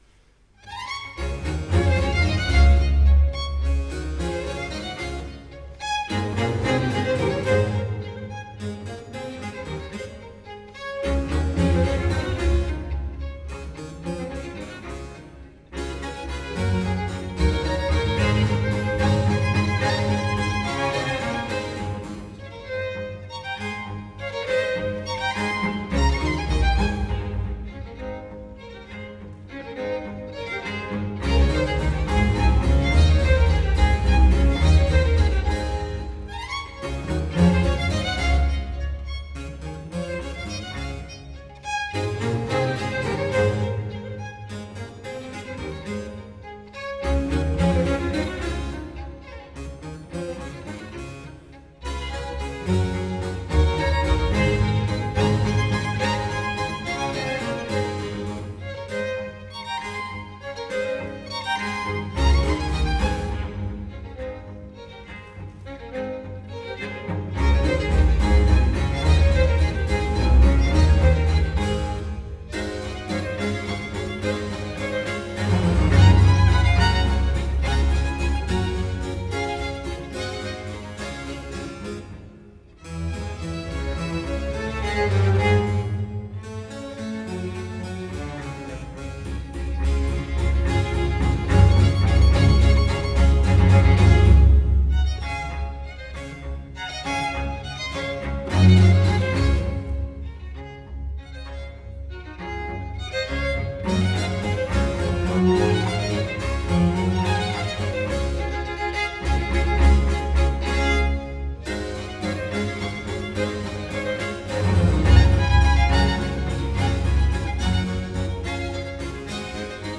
Live recordering 07 ottobre 2017